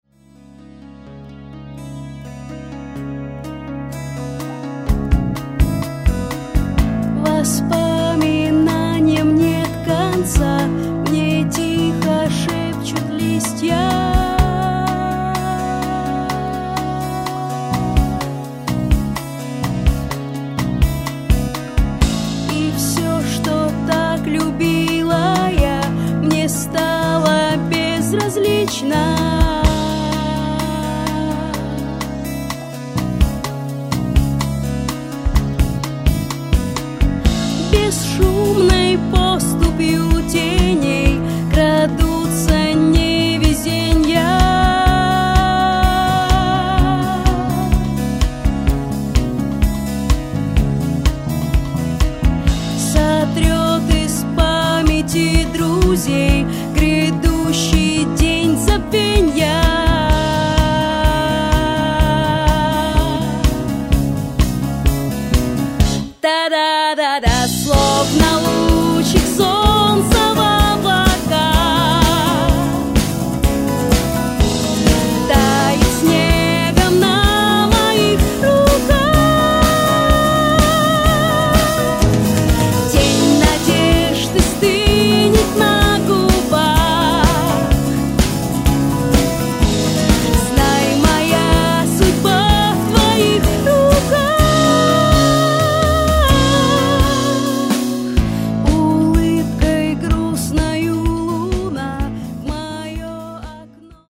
вокал
гитара, гитарный синтезатор
барабаны
бас-гитара